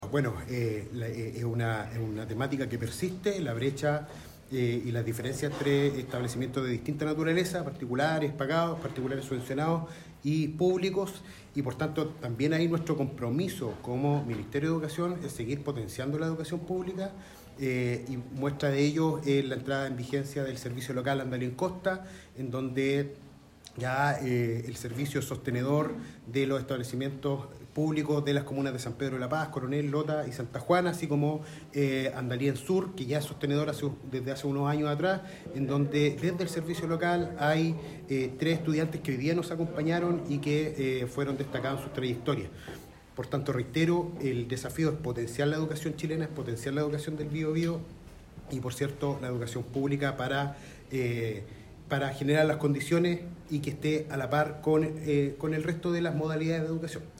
Mientras desayunaban en un mesón amplio en el Salón Biobío de la Delegación Presidencial Regional, las autoridades preguntaron a las y los estudiantes a qué carreras y universidades quieren postular, lo que permitió constatar que la mayoría tiene intenciones de quedarse en la Región del Biobío.